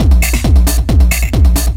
DS 135-BPM D1.wav